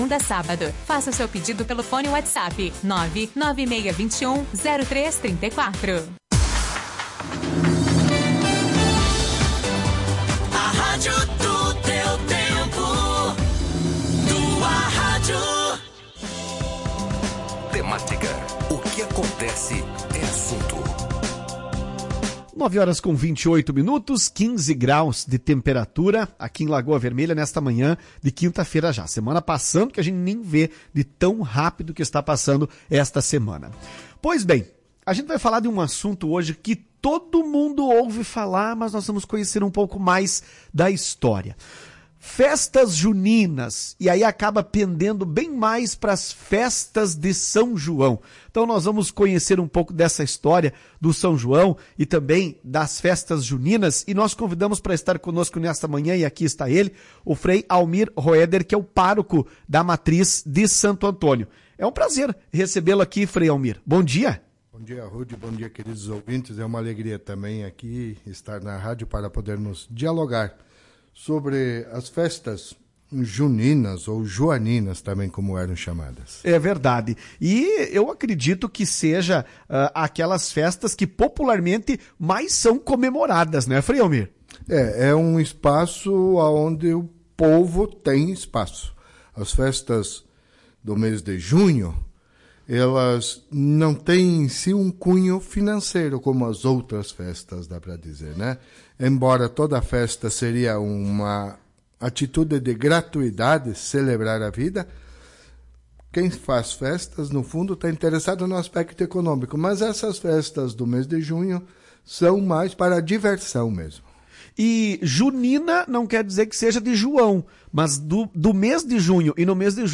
Além da celebração de São João Batista, em junho, também se comemora Santo Antônio e São Pedro. Ouça a entrevista com o frei e saiba mais sobre a vida dos santos e a origem das festas juninas.